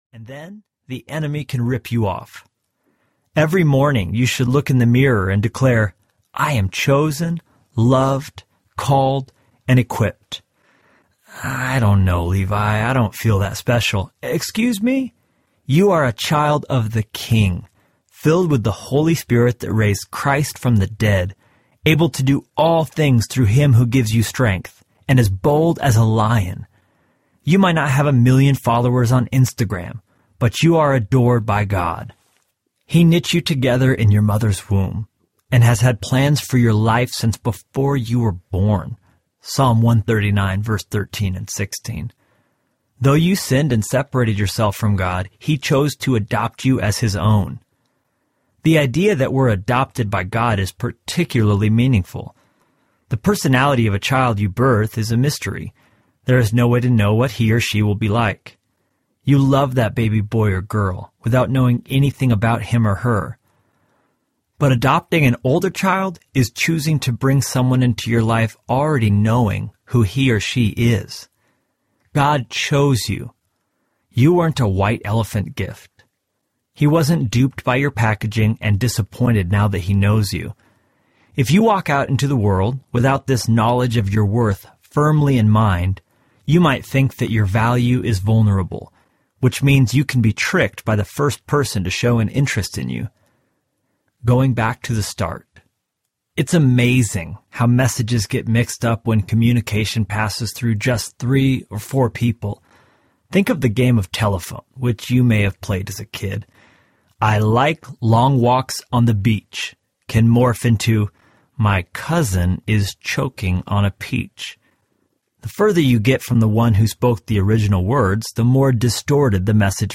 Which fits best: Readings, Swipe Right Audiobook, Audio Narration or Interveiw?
Swipe Right Audiobook